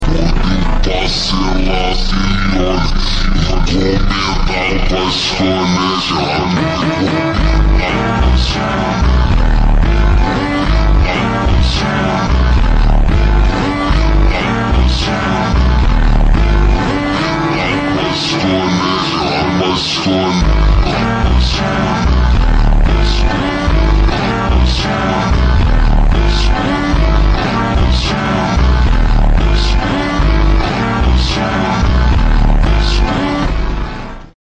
Ultra Slowed Reverb